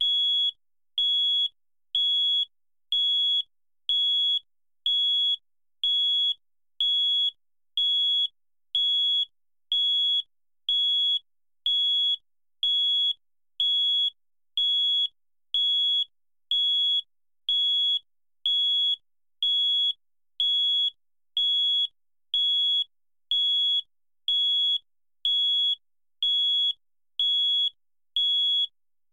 beep-compressed.mp3